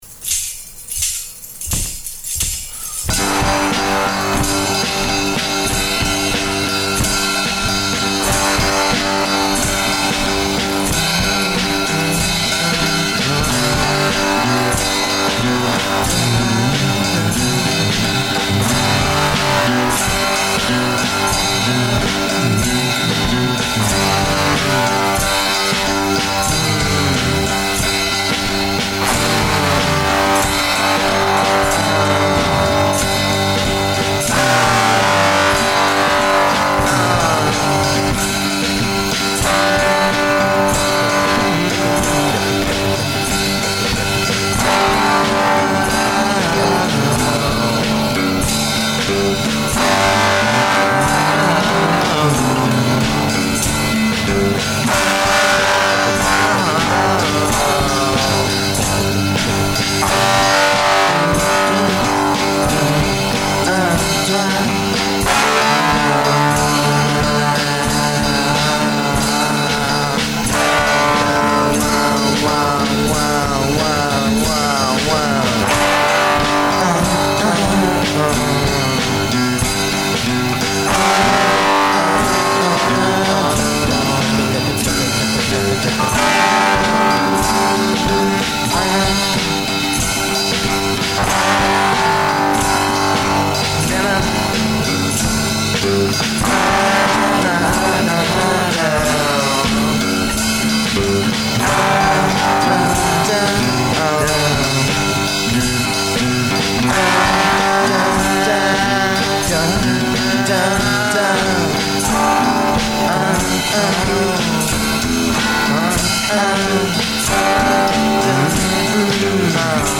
Original Psychedelic Rock.